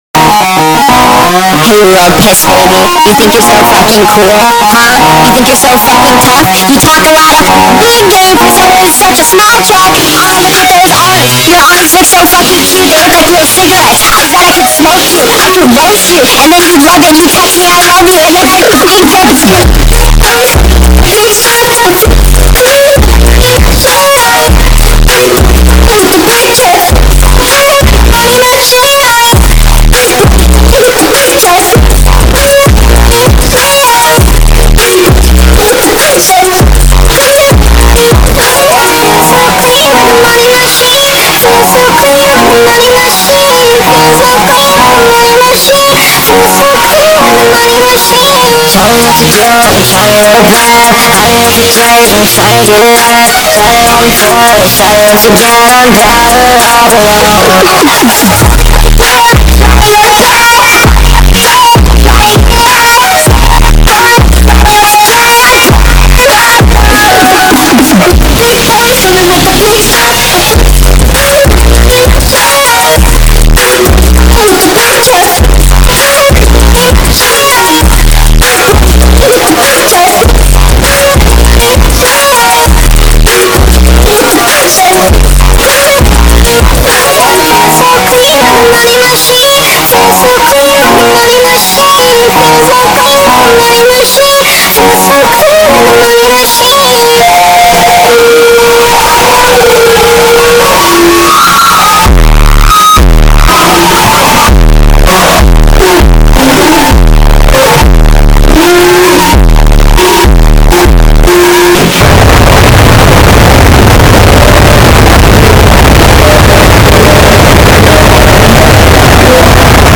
(ear rape)